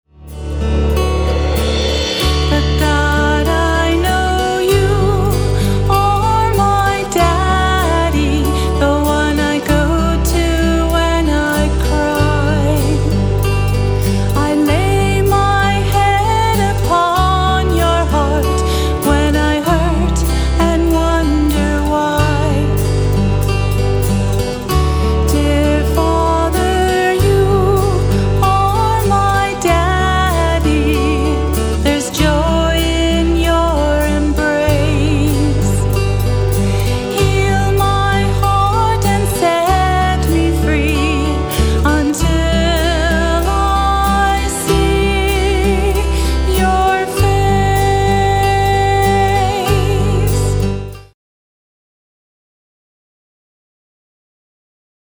The heart of my music ministry is praise and worship!